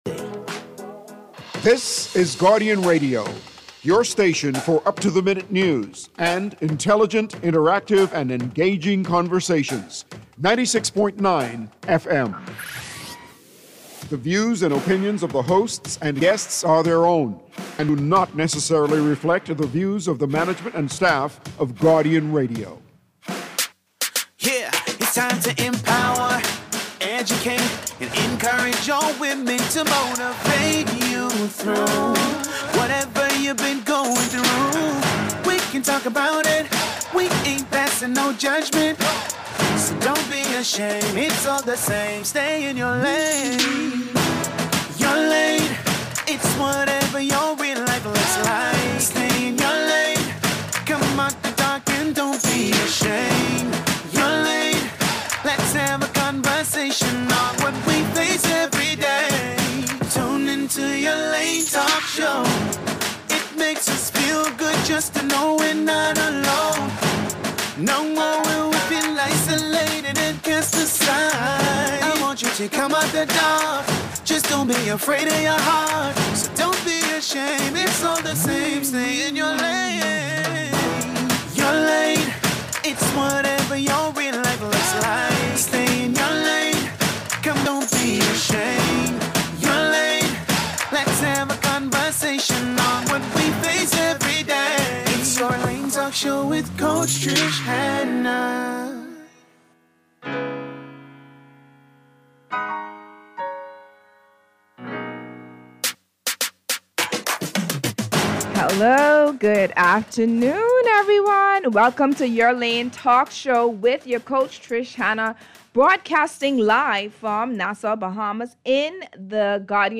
Your Lane Talk Show